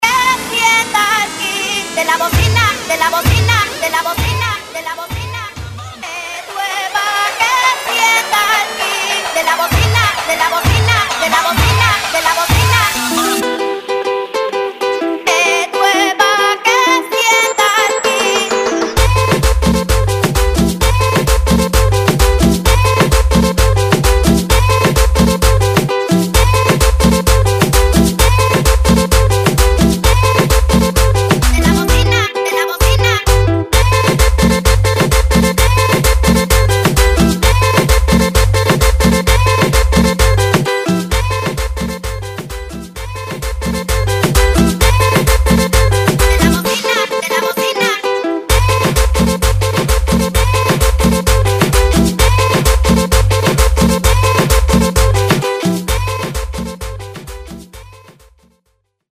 130 / Guaracha